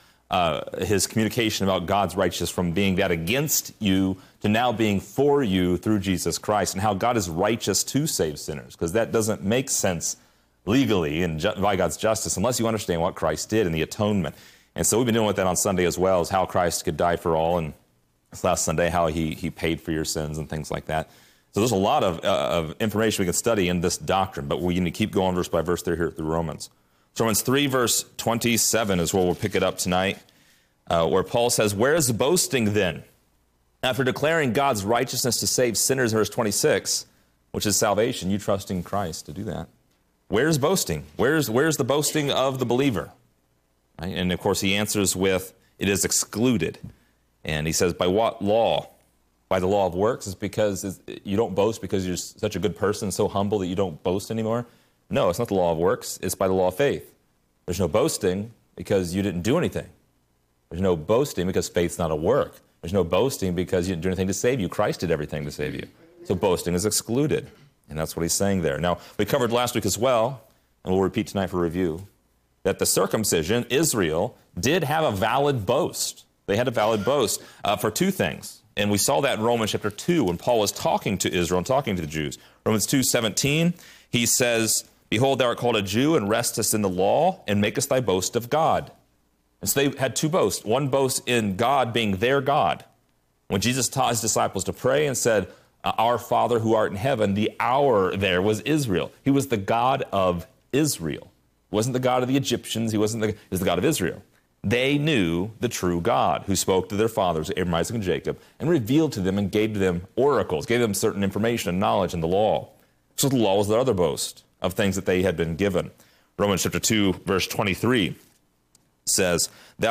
Description: This lesson is part 25 in a verse by verse study through Romans titled: Faith, Flesh, and the Law.